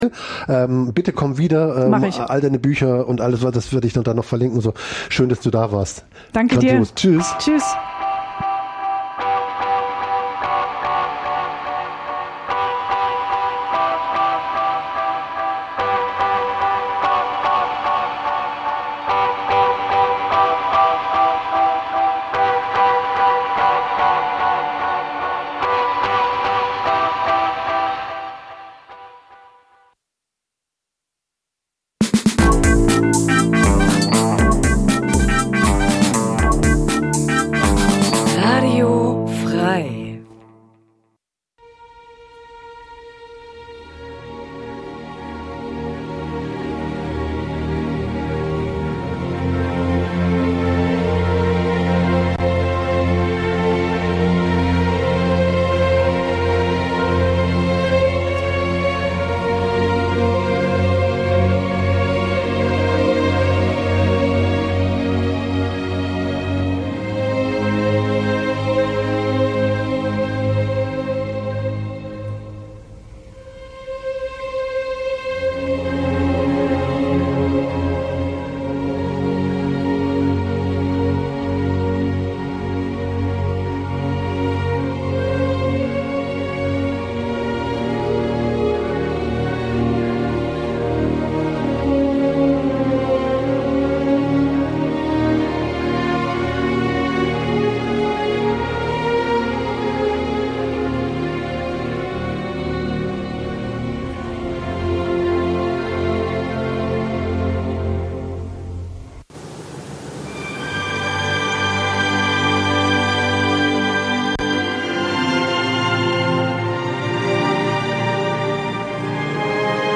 Musiksendung Dein Browser kann kein HTML5-Audio.